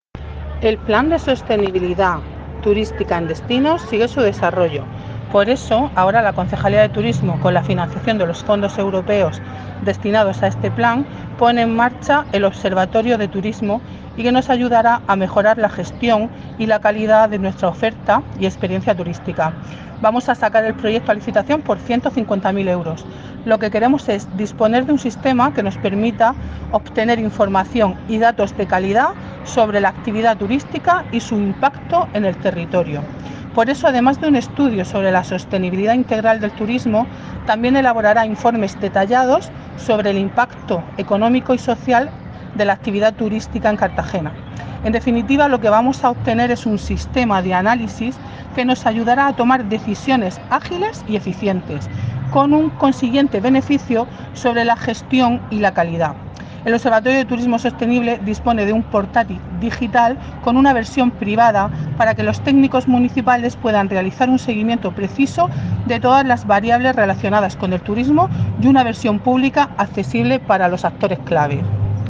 Enlace a Declaraciones de la concejal Beatriz Sánchez